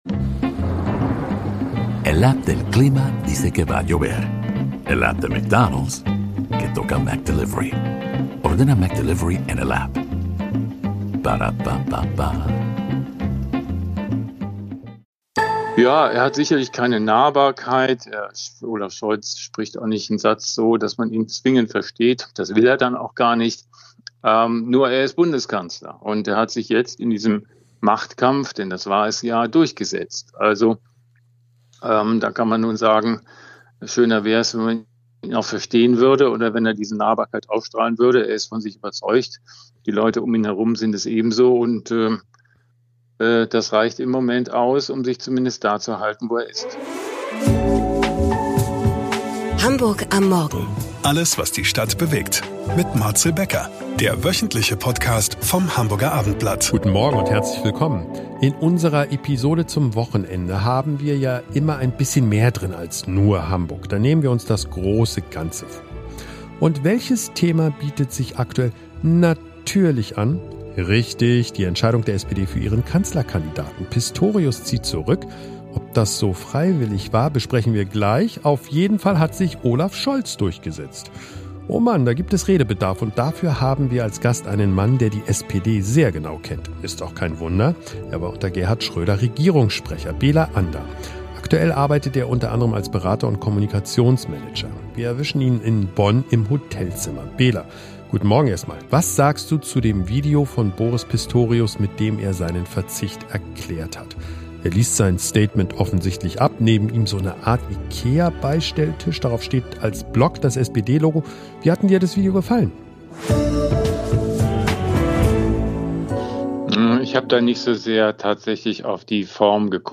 Fakten, Analysen und schonungslose Einblicke – die spannendste politische Diskussion des Wochenendes.